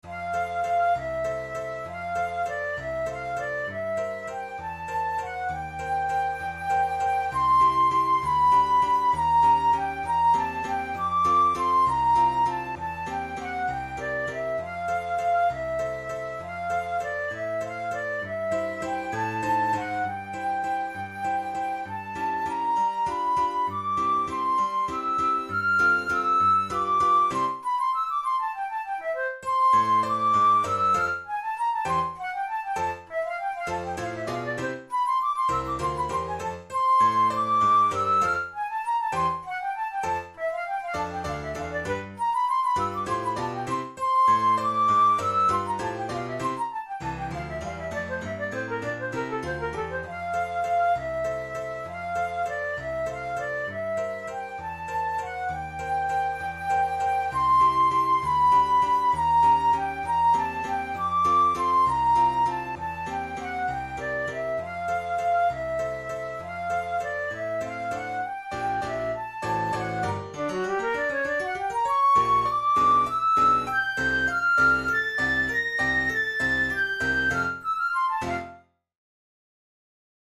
InstrumentationFlute and Piano
KeyF major
Time signature3/4
Tempo66 BPM
Ballet excerpts, Dance tunes, Romantic, Waltzes
tchaikovsky-sleeping-beauty-garland-waltz.mp3